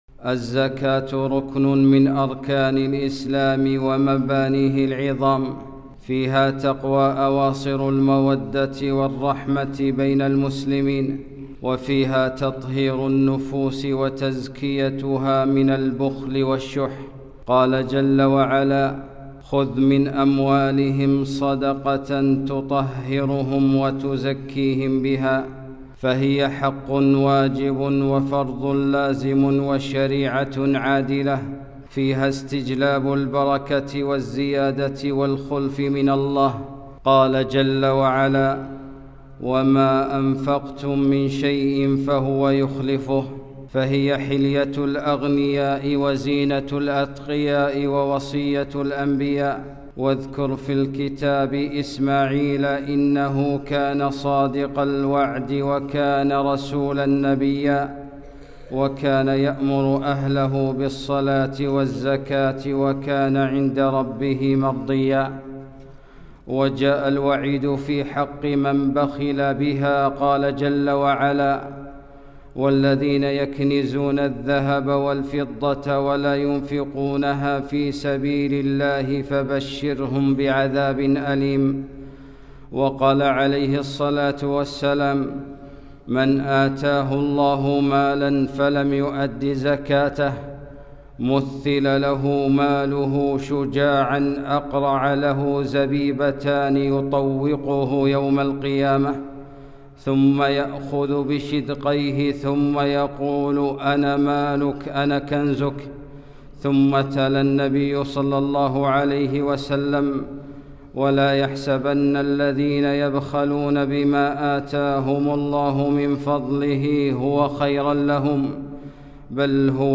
خطبة - خذ من أموالهم صدقة - دروس الكويت